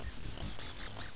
jungle0.wav